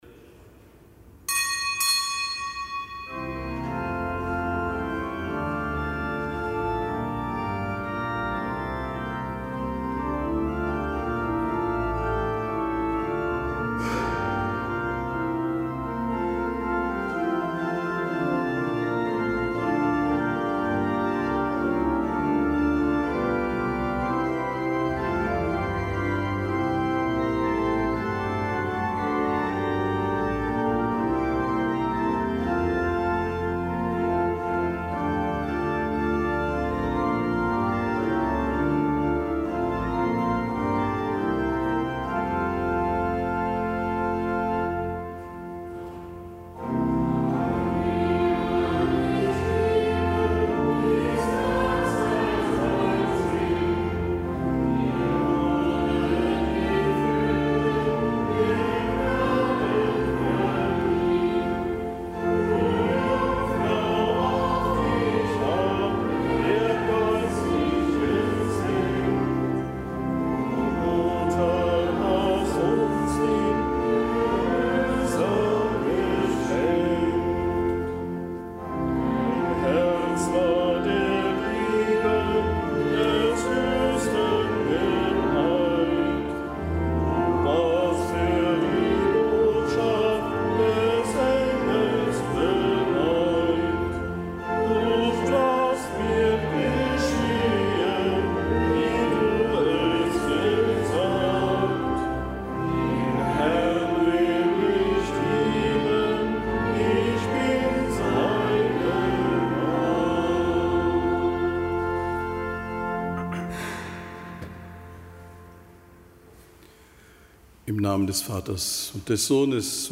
Kapitelsmesse aus dem Kölner Dom am Samstag der siebzehnten Woche im Jahreskreis.